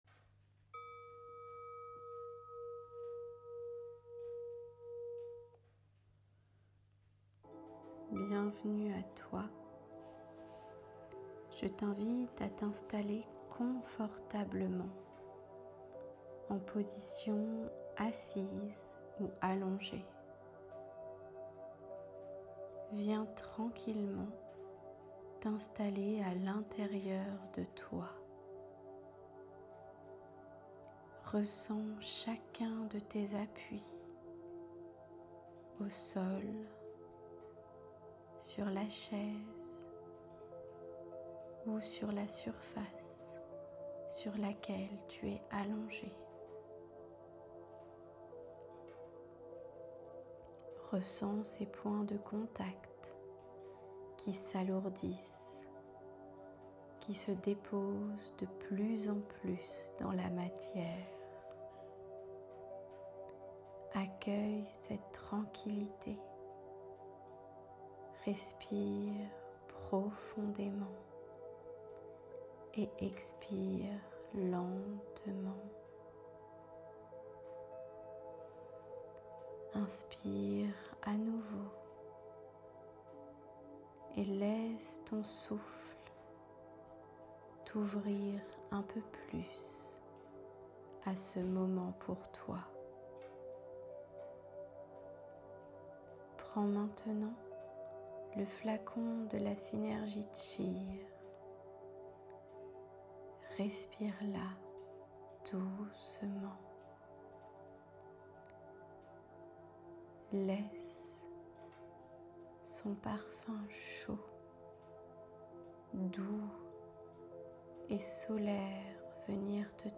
Méditation de la joie
Meditation-de-la-joie.m4a